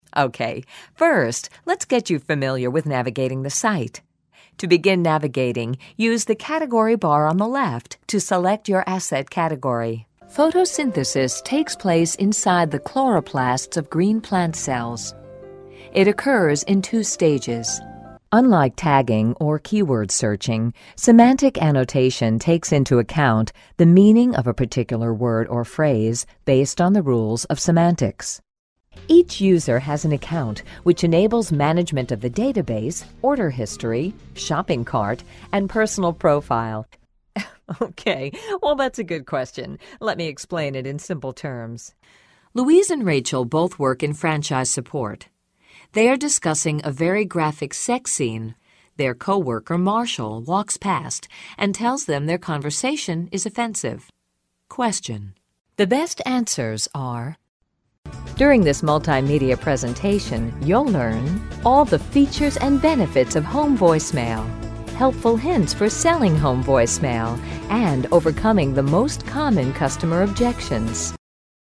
American Female Voice Talent, Narrator, Voice Over commercials, telephone voice, video games, e-learning and medical narrations, characters too
englisch (us)
Sprechprobe: eLearning (Muttersprache):